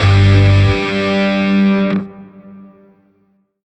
GrineerShawzinMinChordJ.ogg